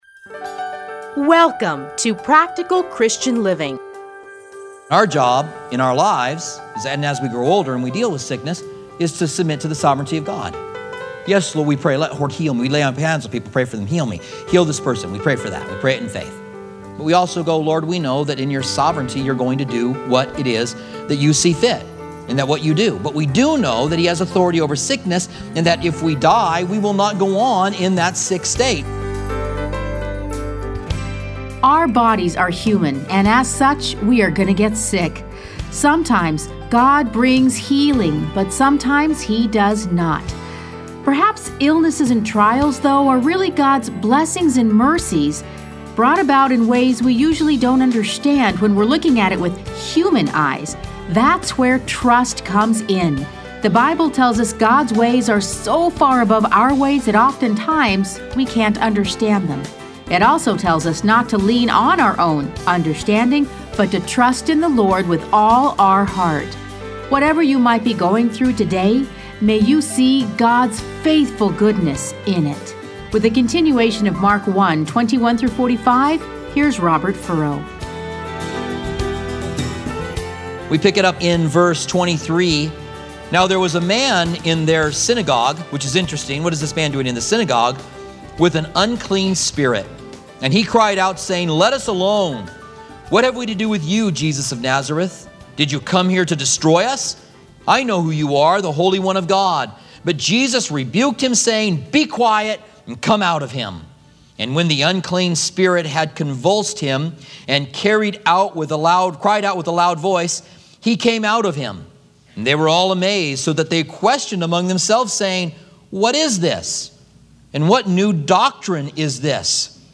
Listen to a teaching from Mark 1:21-45.